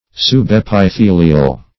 Subepithelial \Sub*ep`i*the"li*al\